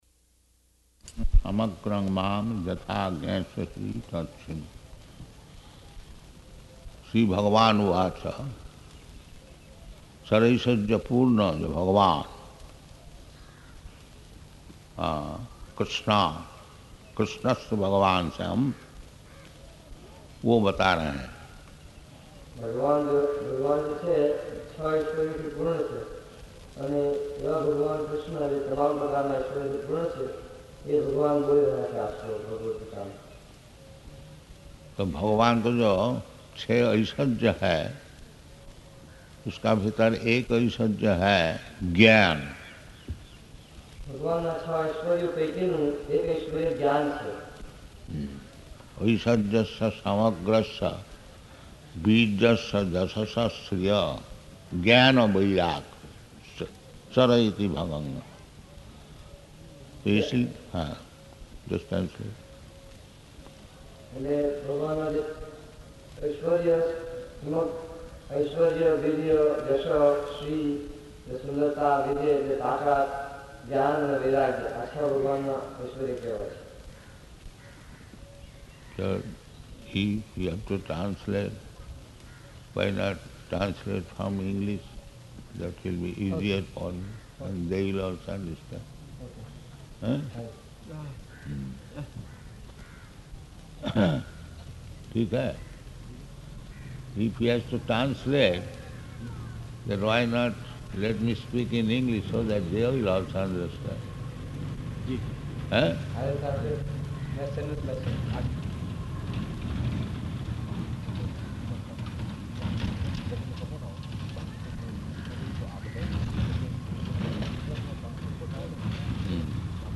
Bhagavad-gītā 7.1 [with Translator]
Location: Sanand
[begins lecture in Hindi] [translator translates]